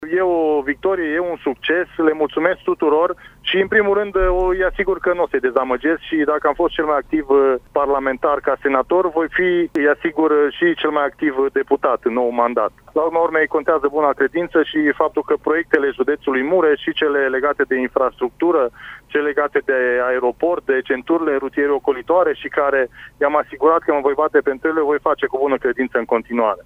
Marius Pașcan a dorit să le mulțumească celor care au votat și a promis că se bate în continuare pentru proiectele mureșenilor și în viitorul mandat de parlamentar: